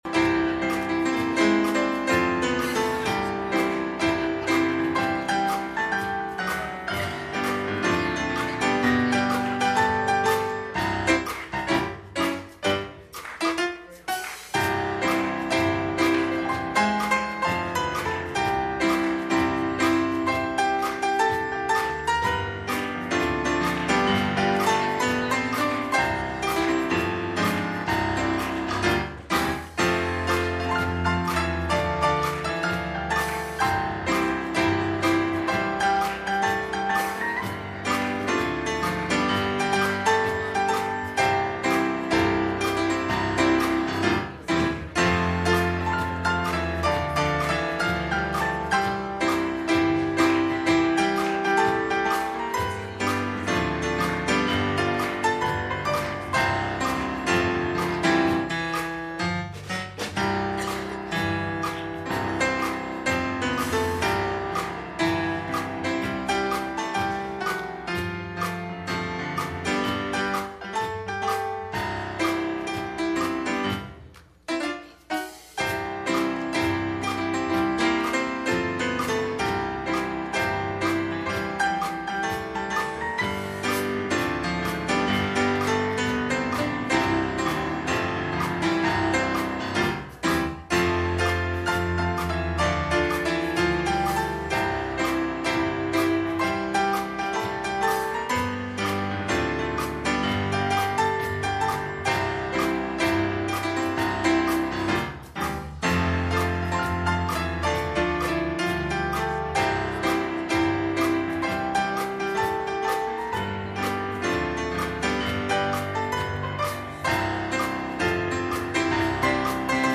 Luke 12:48 Service Type: Sunday Evening « Selective Service